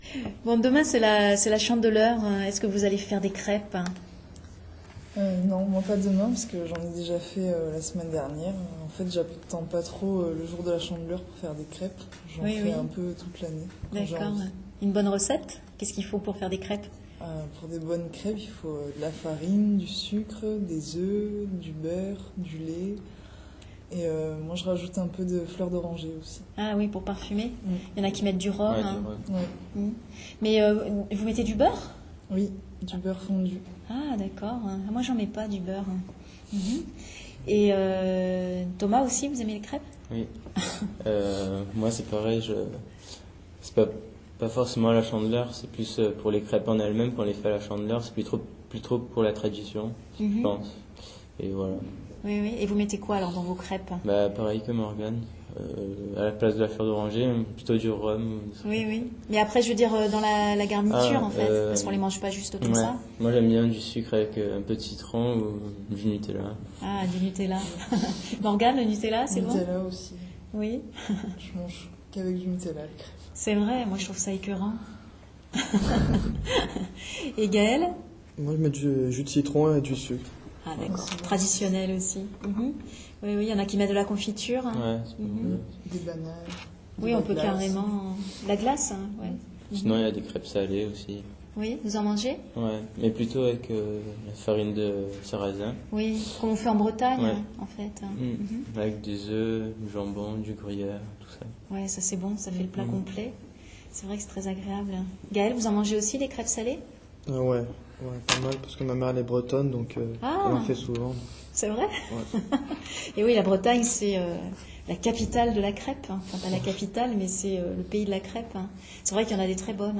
Dans le sud, on ne fait pas la différence entre les mots en « ai » et ceux en « é » en fait.